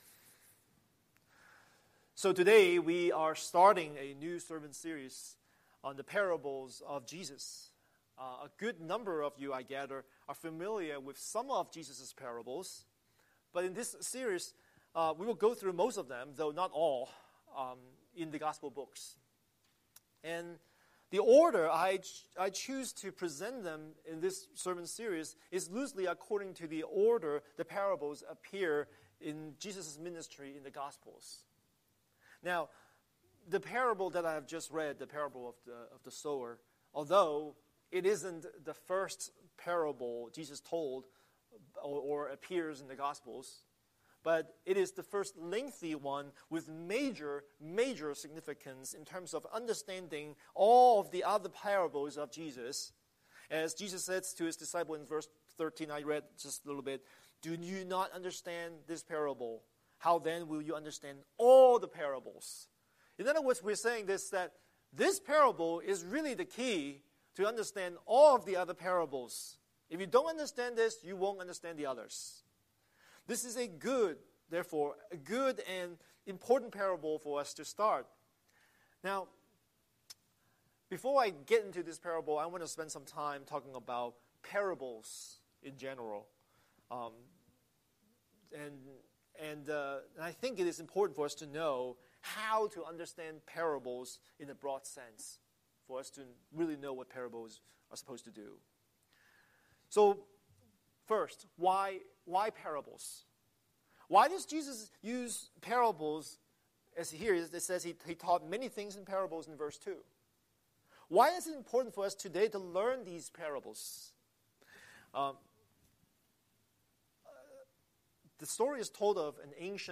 Scripture: Mark 4:1–20 Series: Sunday Sermon